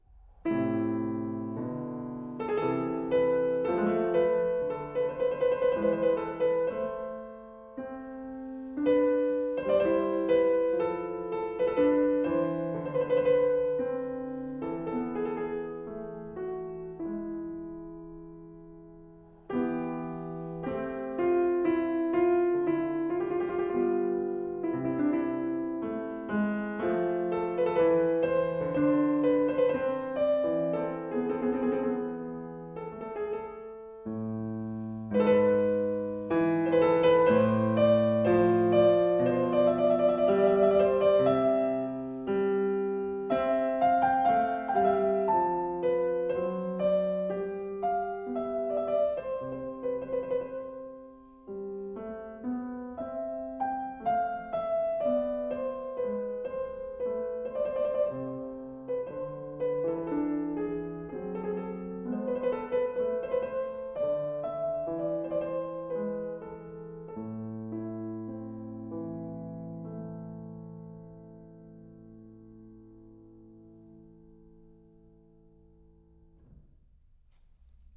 Recorded at Fazioli Concert Hall of Sacile (PN), Italy
The recording quality also is first-class...